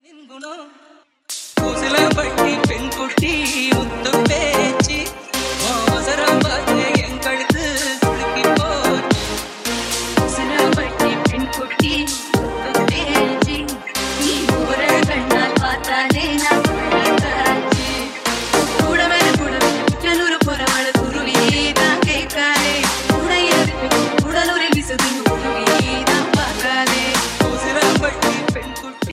tamil ringtonebgm ringtoneenergetic ringtone